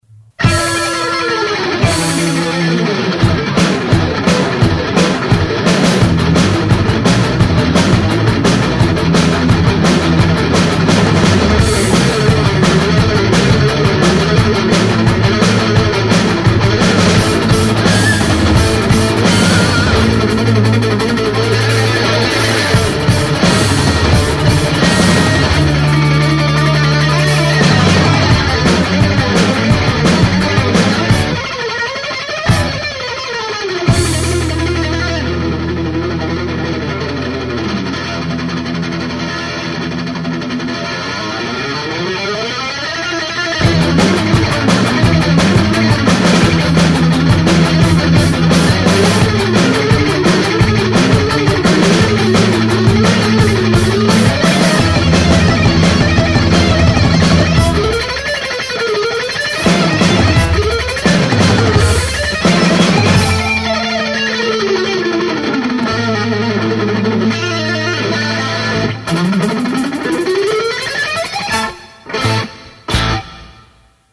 金属版《野蜂飞舞》！！！